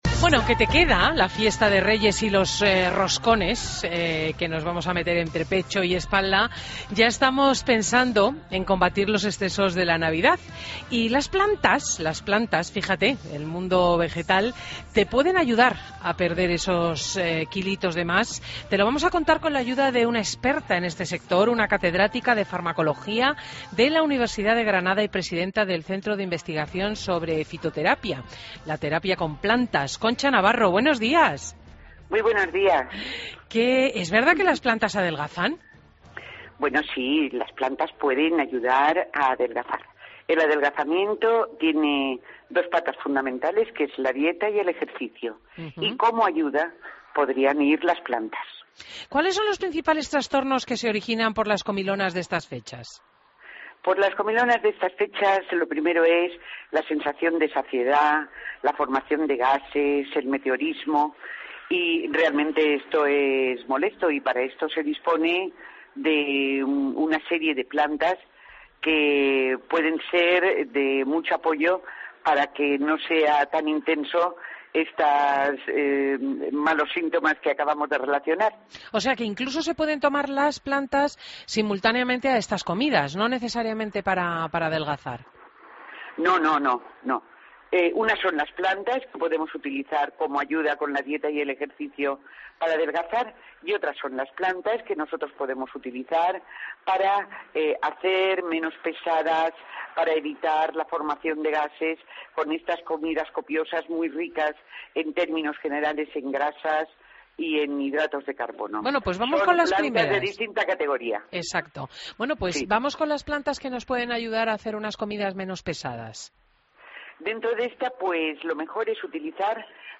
Entrevistas en Fin de Semana Entrevista